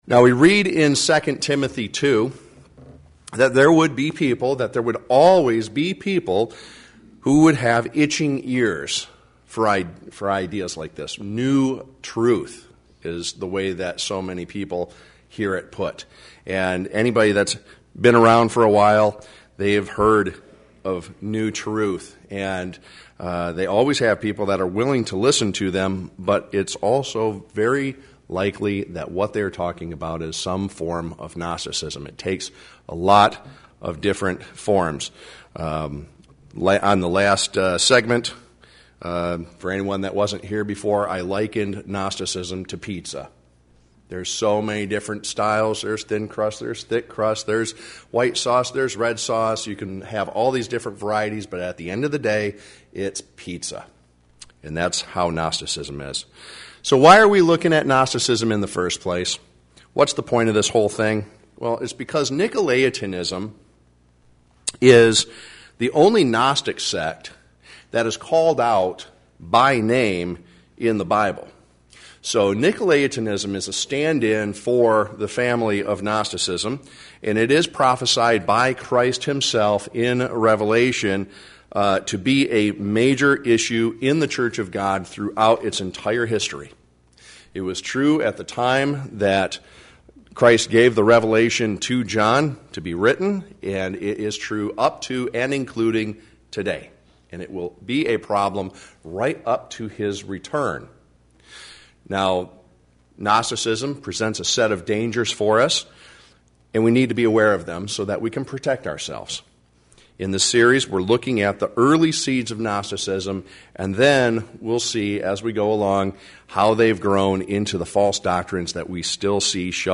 Gnosticism Bible Study: Part 5